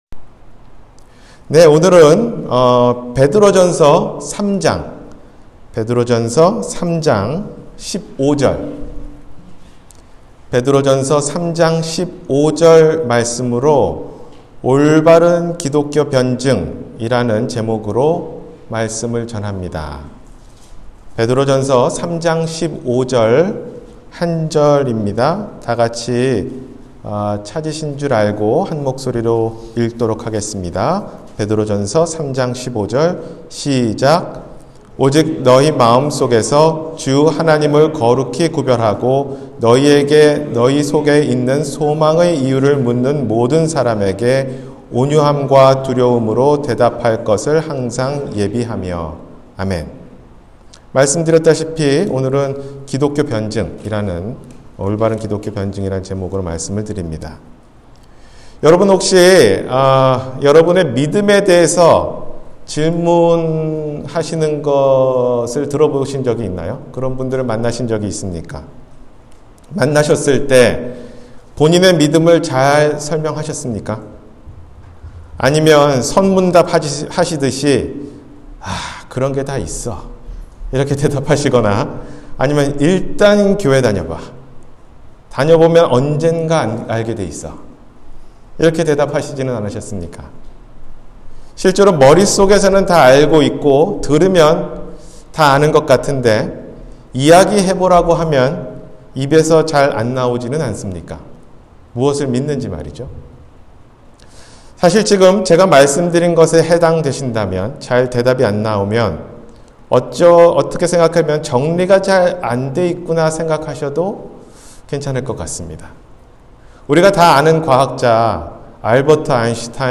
올바른 기독교 변증 – 주일설교